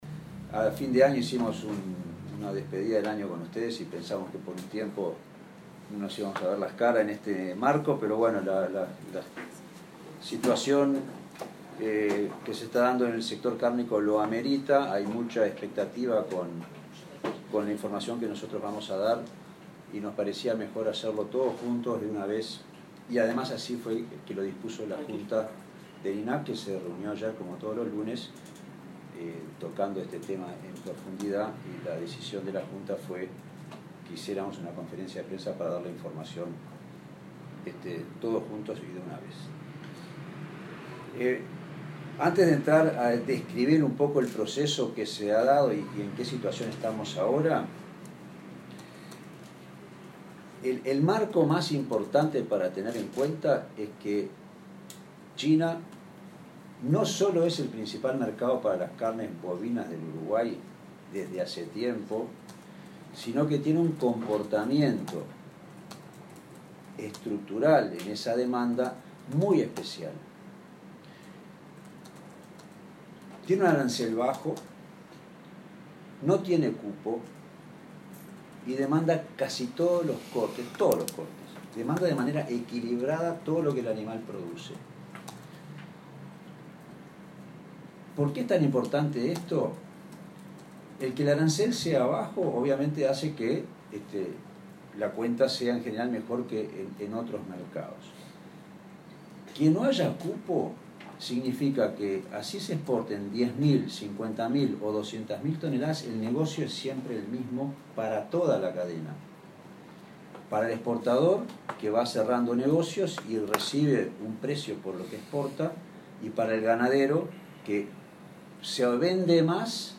Conferencia de prensa: